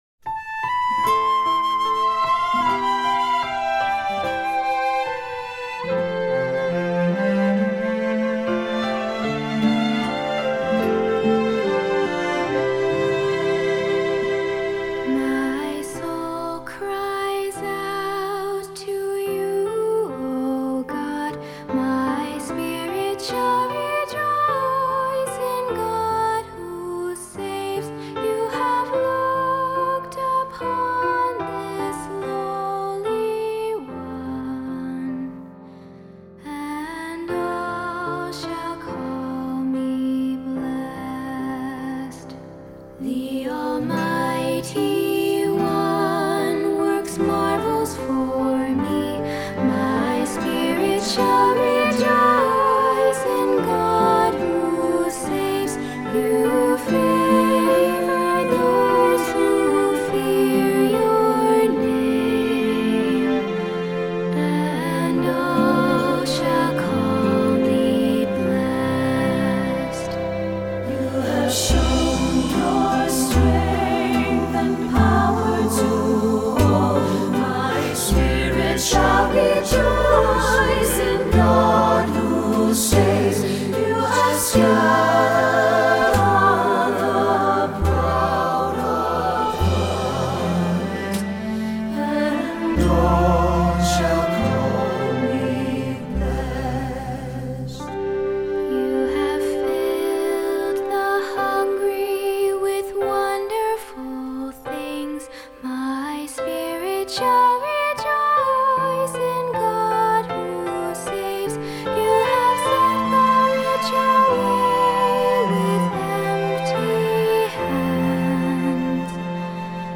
Voicing: Soloist or Soloists,SATB